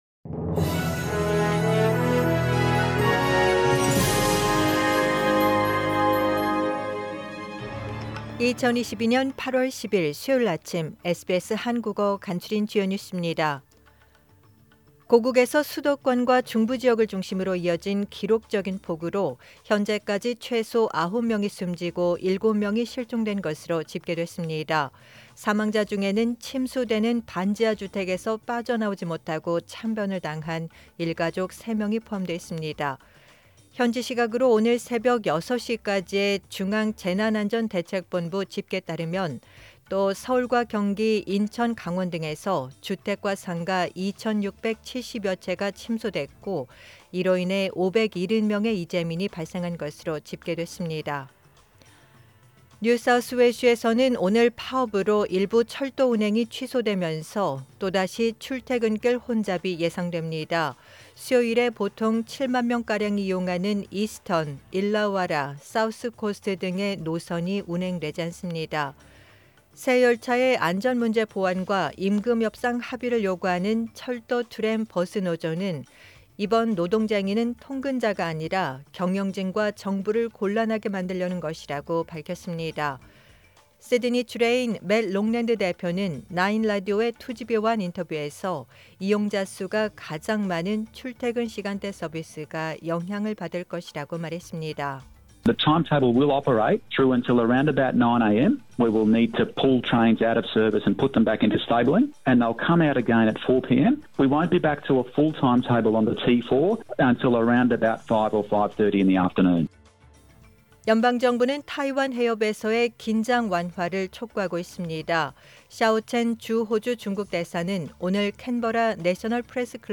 SBS 한국어 아침 뉴스: 2022년 8월 10일 수요일
2022년 8월 10일 수요일 아침 SBS 한국어 간추린 주요 뉴스입니다.